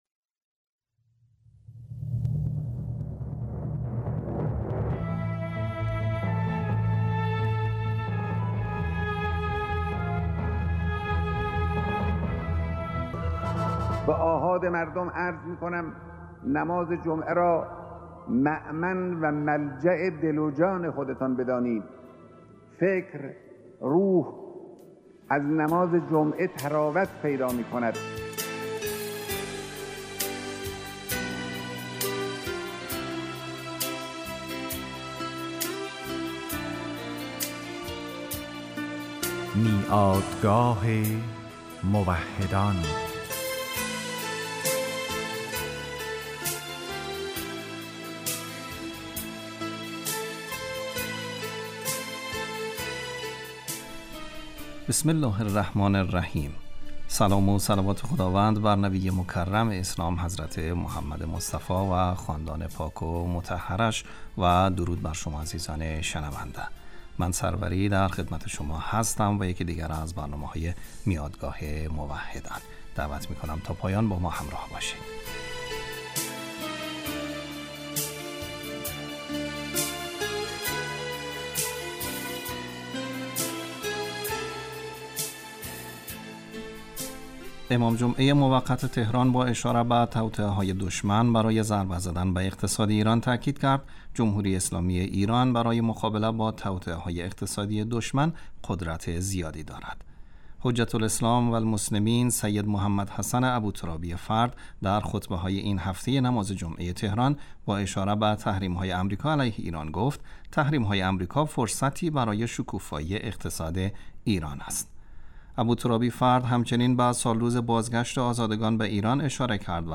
چکیده ای از خطبه های نماز جمعه تهران، قم، اصفهان، کابل، مزار شریف